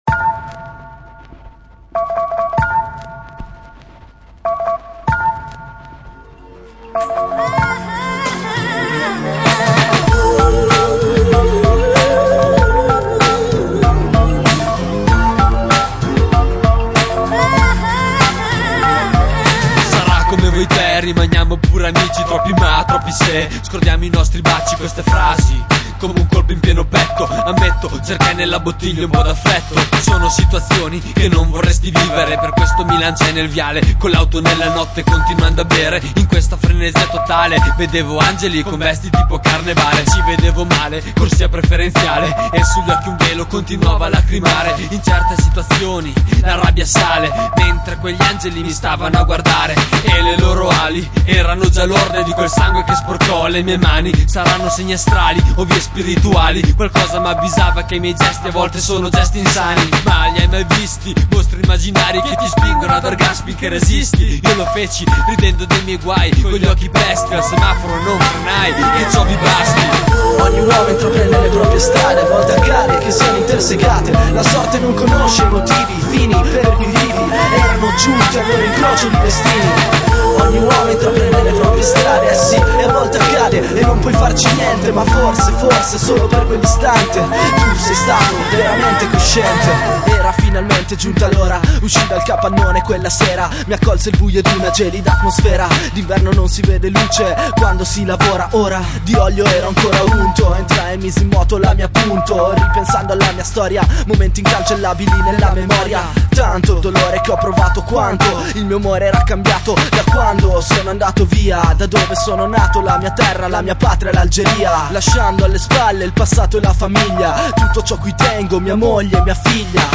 2 mc di Bologna